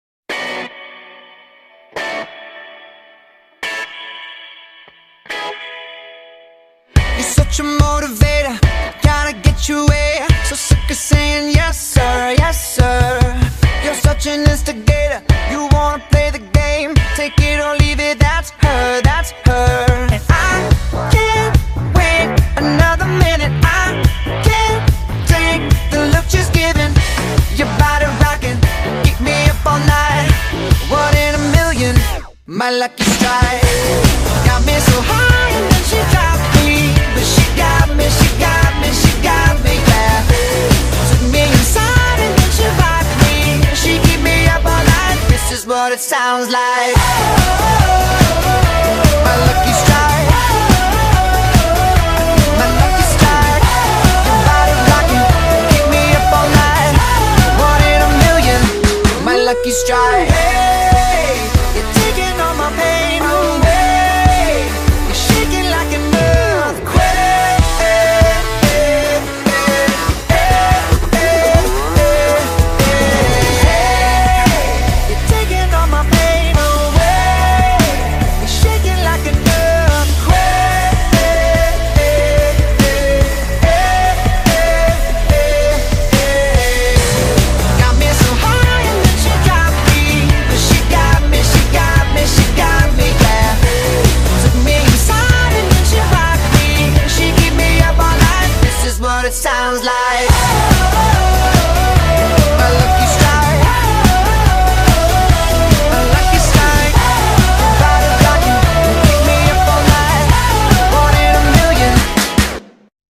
BPM72-144
Audio QualityMusic Cut